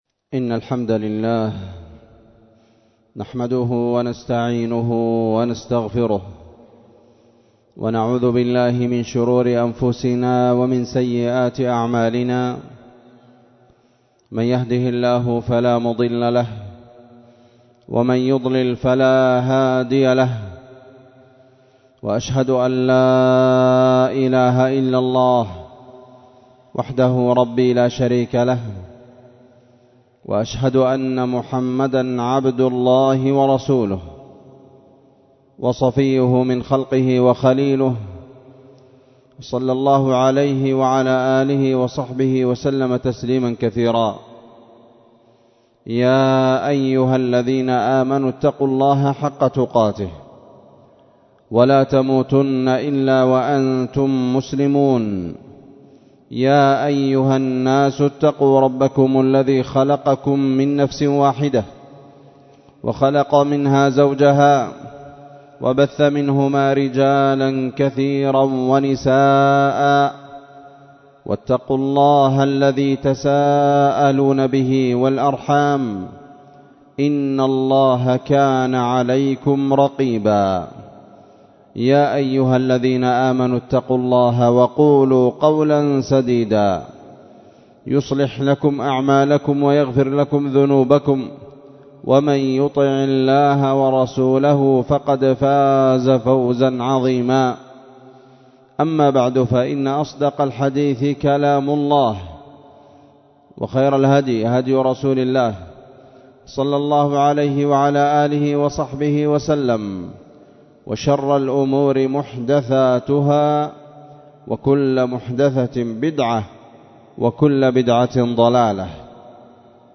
خطبة جمعة بعنوان الكنوز النواضر في العشر الأواخر